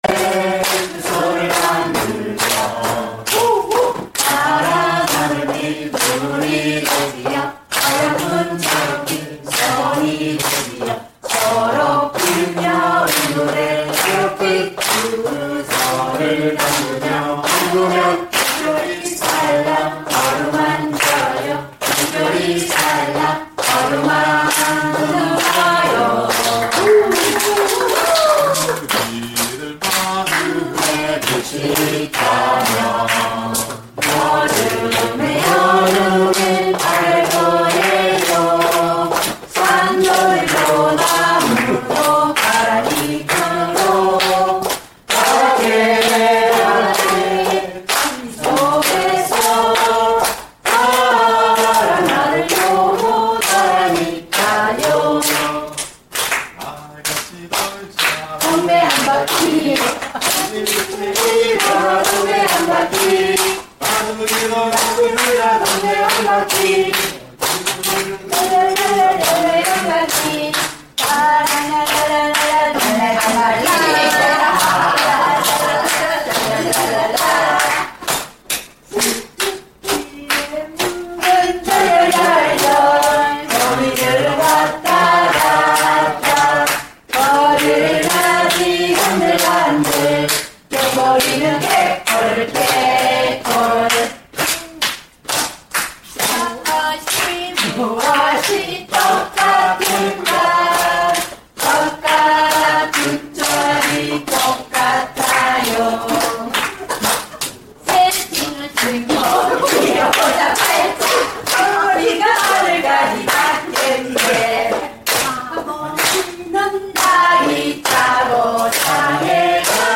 2019년 6월 사제성회의날...아주 특별한 사목협의회 개최 #5
흥겨운 오락과 신앙나눔의 시간으로 이어집니다.